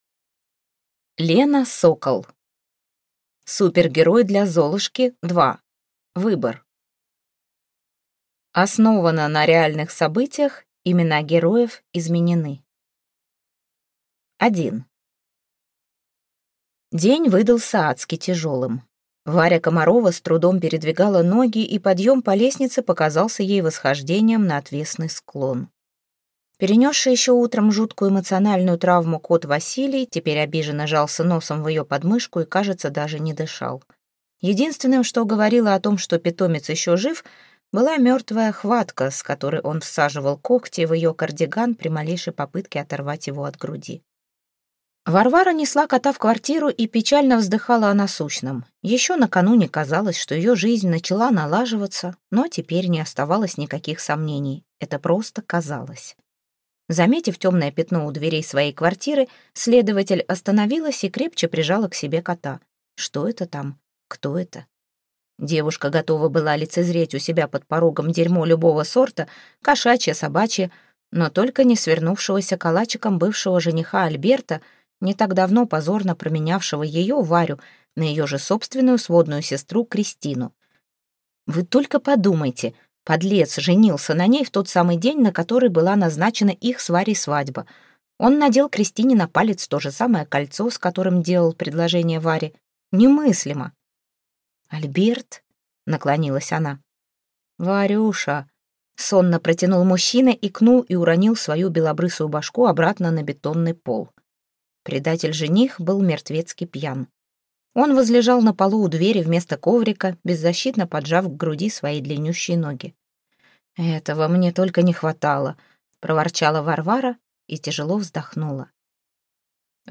Аудиокнига Супергерой для Золушки – 2: Выбор | Библиотека аудиокниг